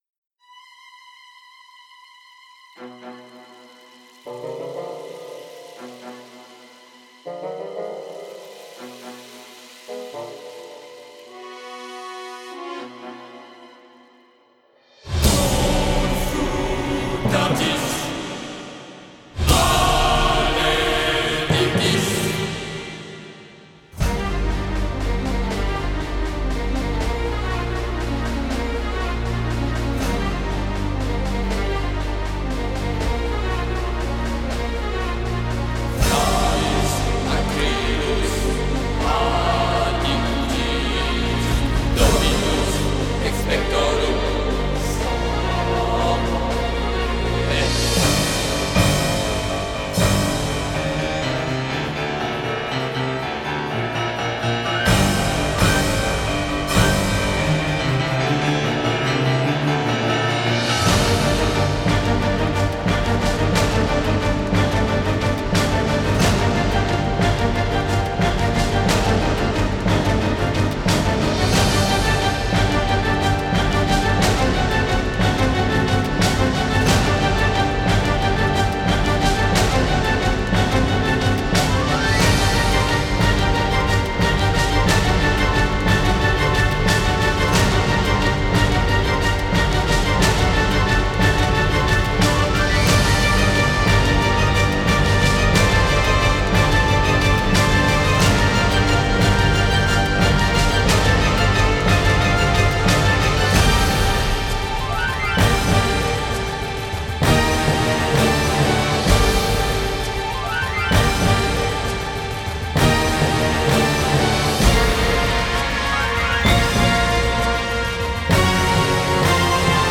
Барочное-лирическое-эпическое.